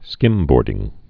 (skĭmbôrdĭng)